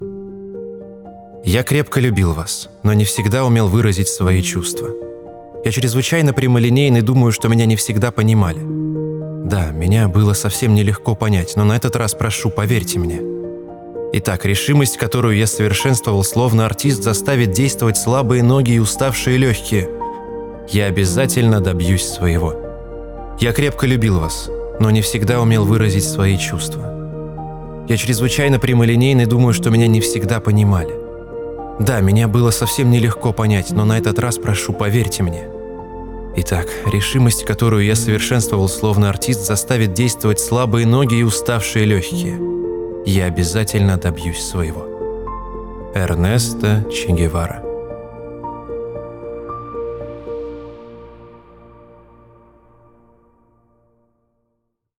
ЗАКАДР